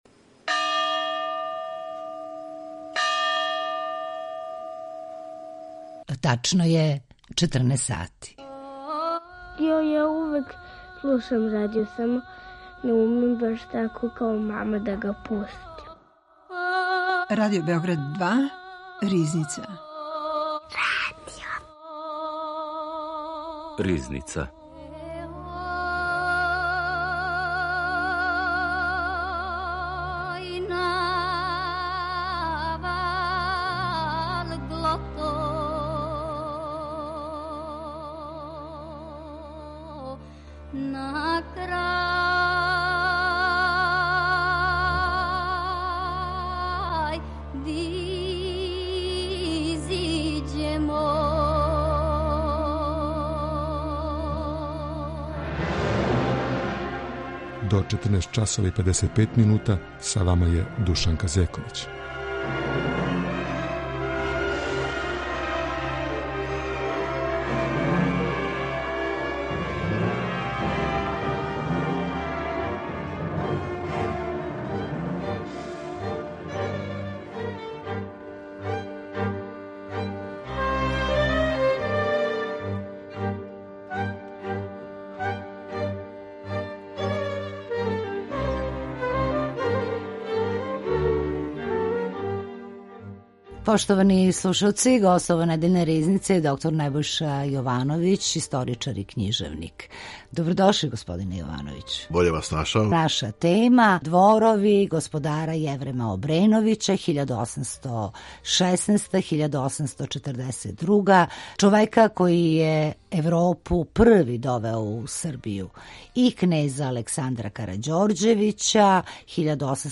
историчар и књижевник